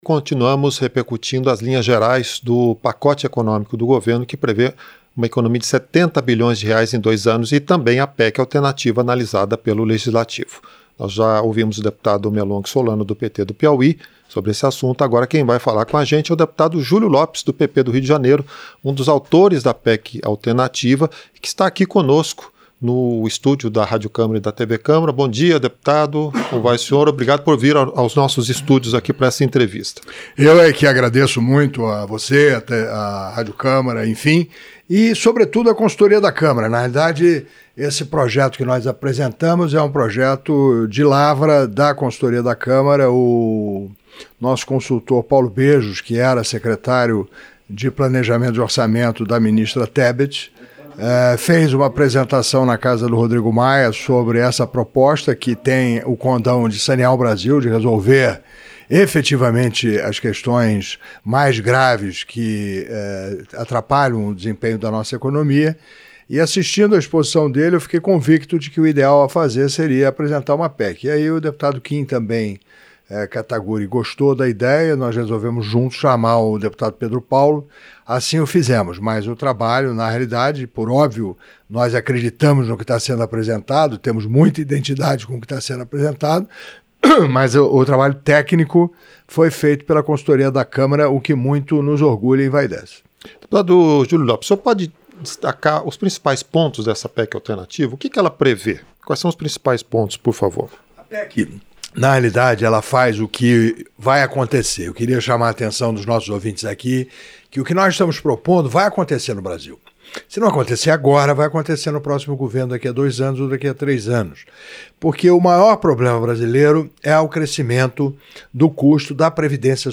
Entrevista - Dep. Julio Lopes (PP-RJ)